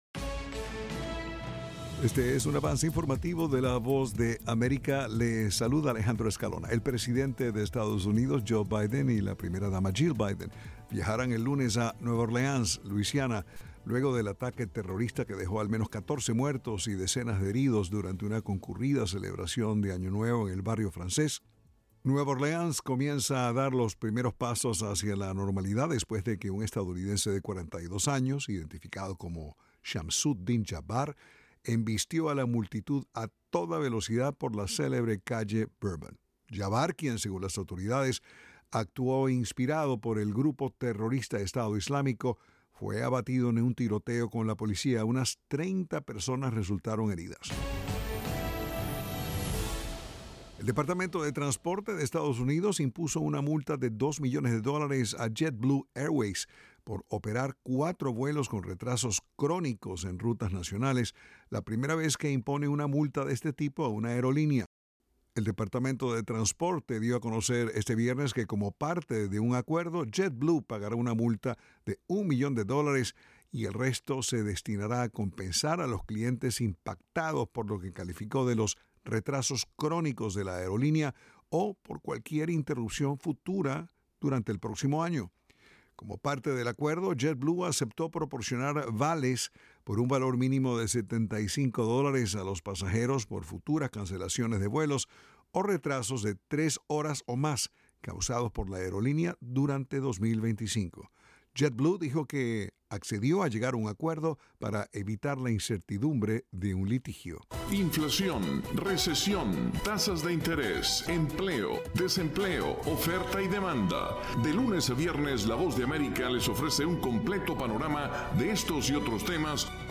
El siguiente es un avance informativo de la Voz de América. Informa desde Washington